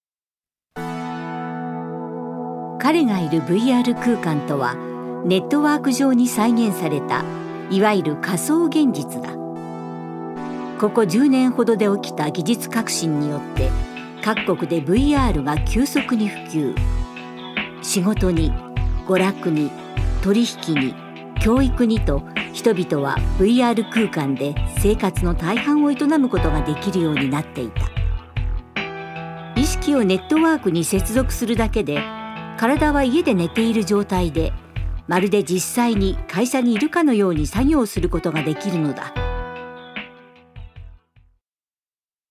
ナレーション４